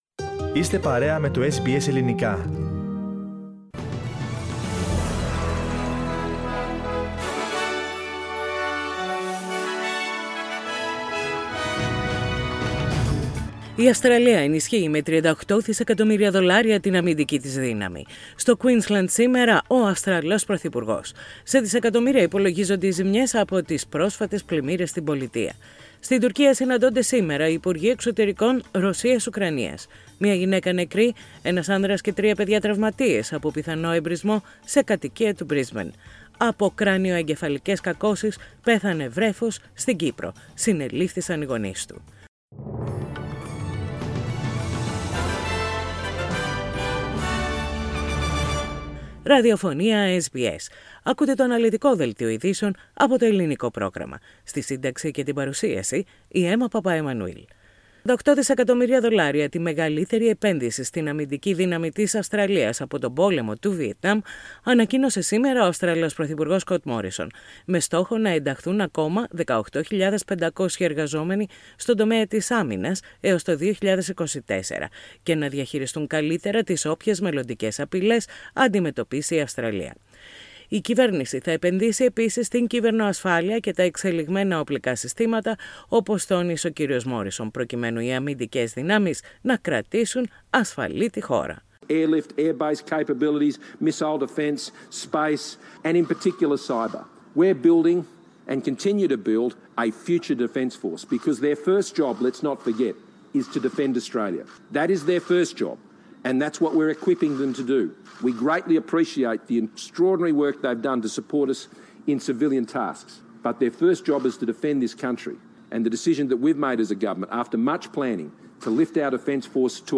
Main bulletin of the day with the news from Australia, Greece, Cyprus and the rest of the world.